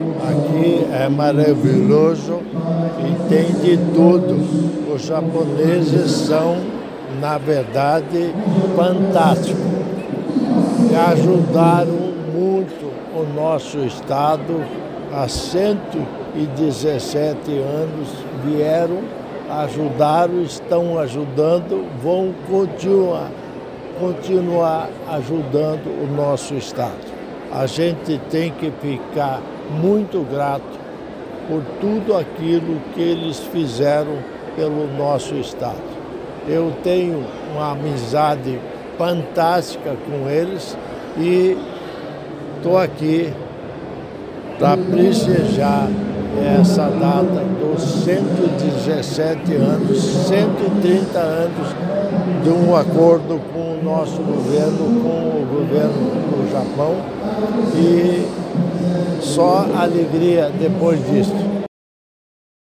Sonora do governador em exercício Darci Piana sobre os laços históricos entre Paraná e Japão durante o IMIN Matsuri